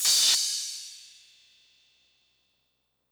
crash 4.wav